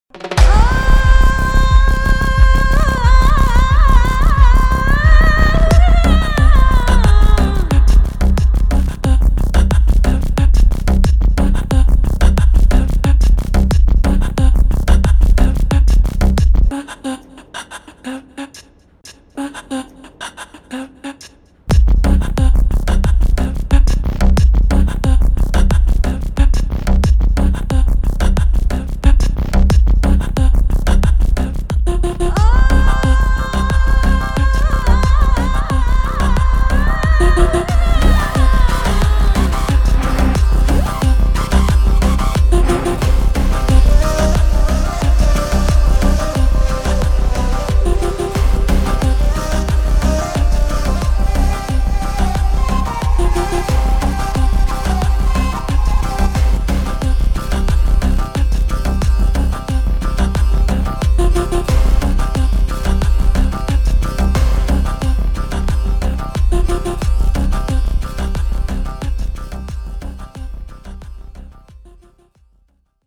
in HD quality without dialogues and disturbances
removed dialogues and disturbances like an official BGM.